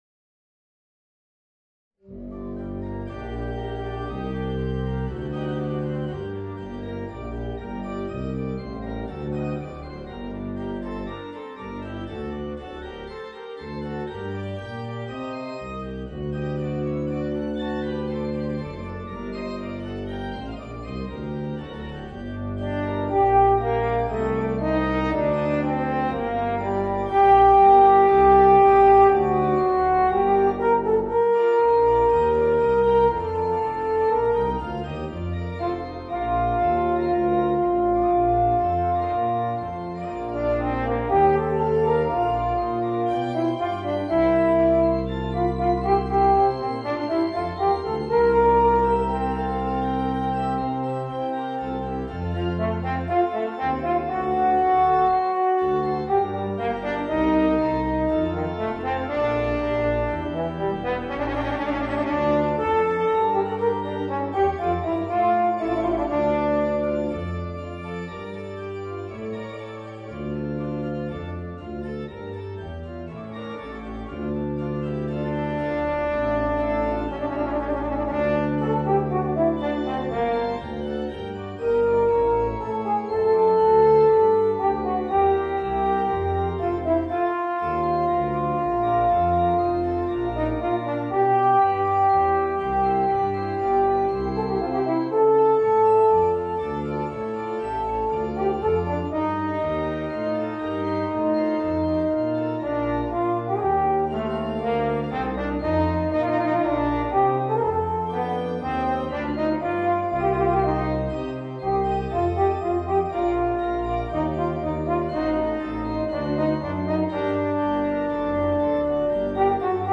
Es-Horn & Orgel